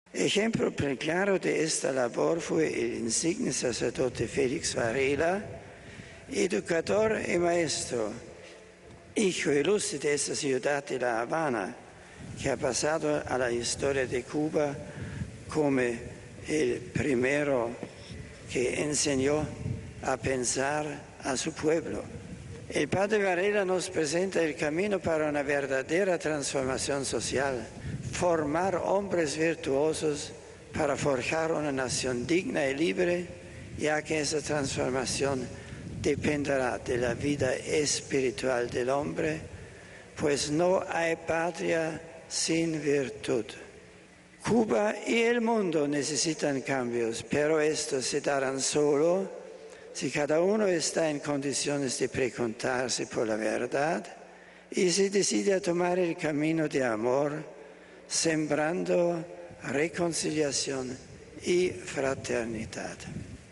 Y subrayó como ejemplo al Padre Félix Varela, añadiendo que Cuba y el mundo necesitan cambios, afianzados en la verdad, el amor, la reconciliación y la fraternidad. Escuchemos la voz del Papa en la Plaza de la Revolución en La Habana: RealAudio